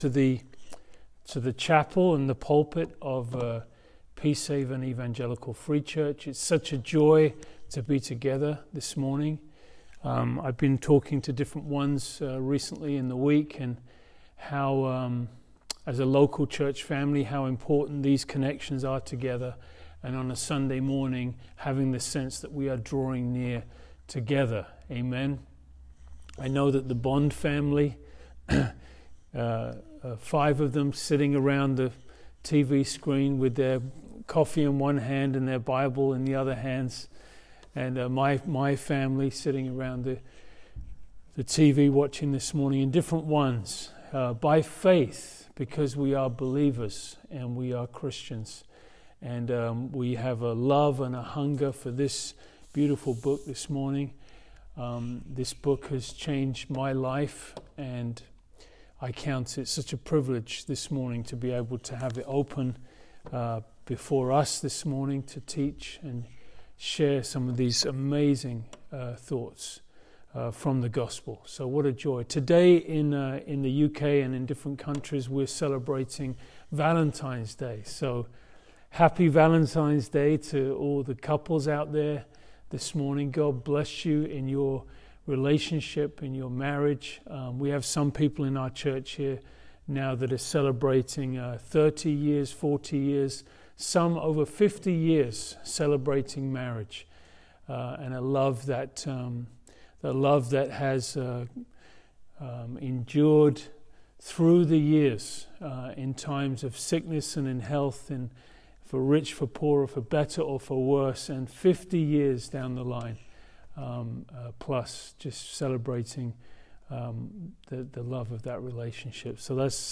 Yet God seeks to prove His love towards us in our best and worst state. Sit back, enjoy this sermon and allow God to minister His personal love to you today.